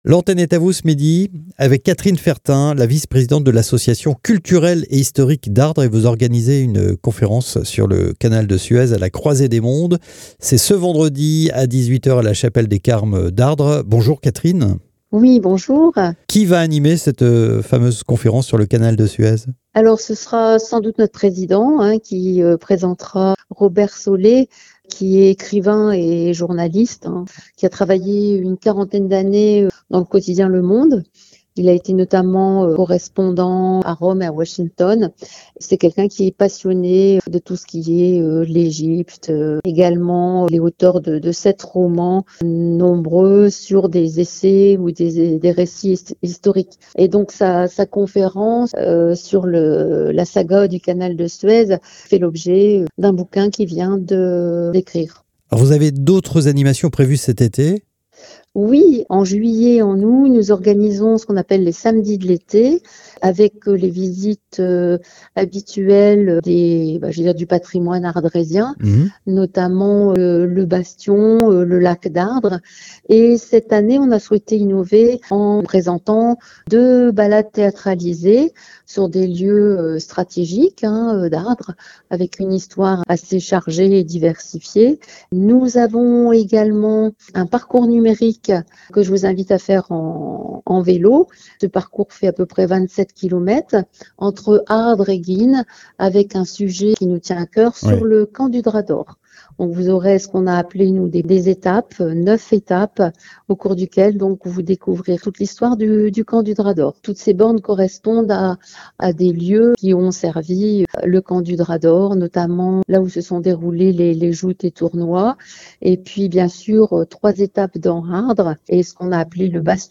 Présentation des manifestations de l'été 2025, à la radio...
annonce-interview-delta-canal-suez.mp3